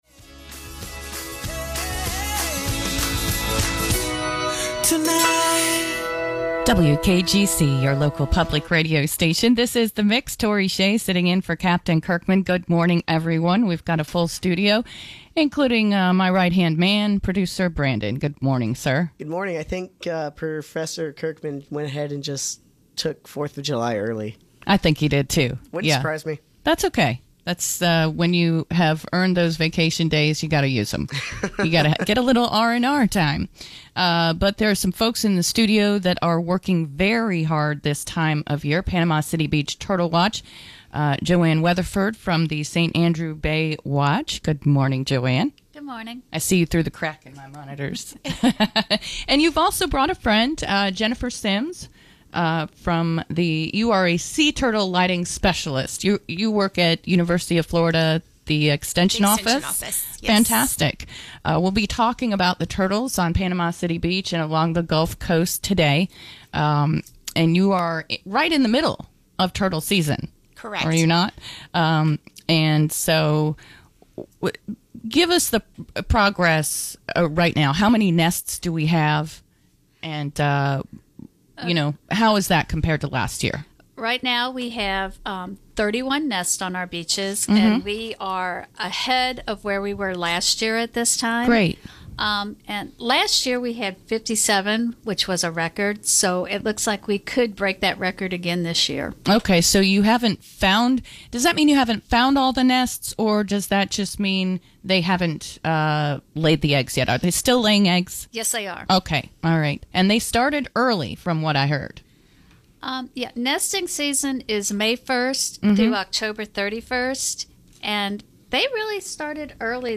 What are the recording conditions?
We start the week off on The Morning Mix as St. Andrew Bay watch joins us in Studio A! We talk about turtle mating season, how you can help and more!